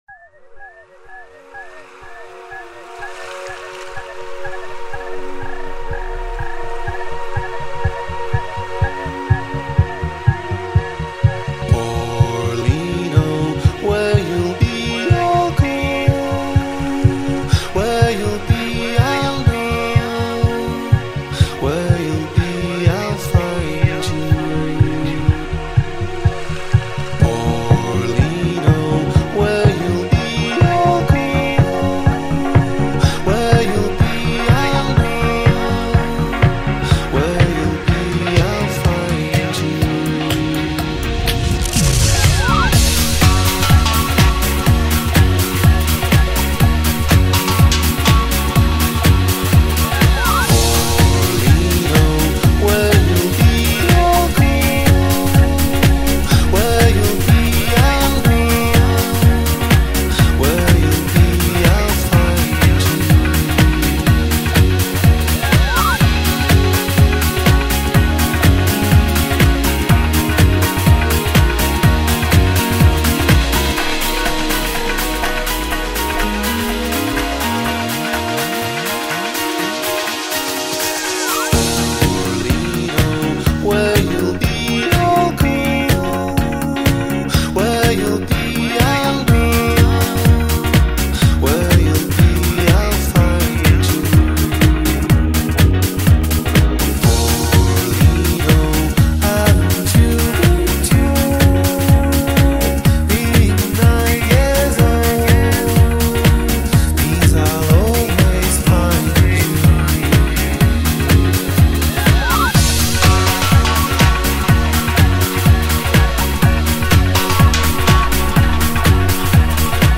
BPM124
Audio QualityPerfect (High Quality)
A very melancholy song, always takes me back...